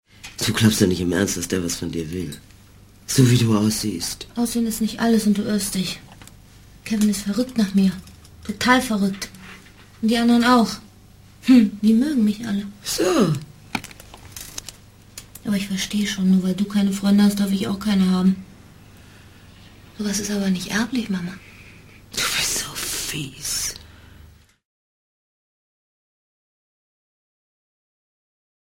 deutsche Sprecherin und Schauspielerin.
Sprechprobe: eLearning (Muttersprache):
german female voice over artist